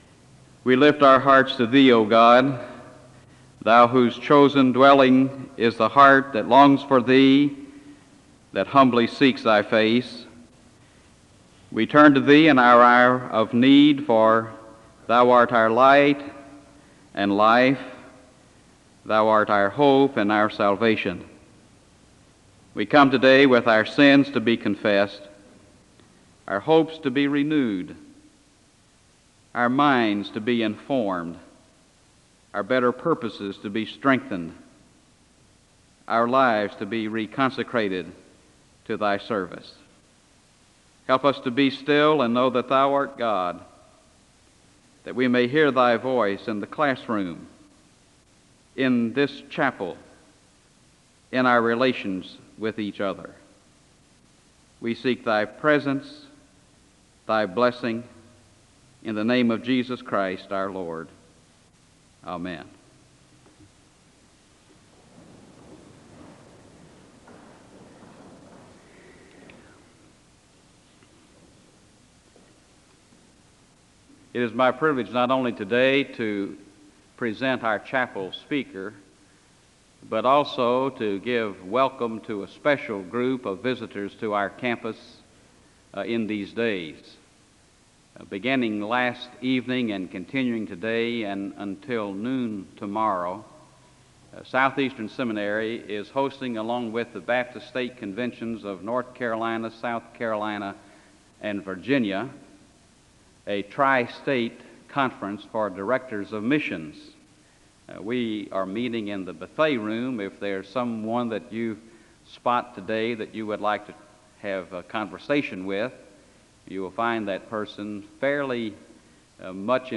Chapel opens with prayer (0:00-1:03).
He concludes by saying that Southern Baptists can lead with their strengths in the current society (13:40-15:54). The service closes in prayer (15:54-16:27).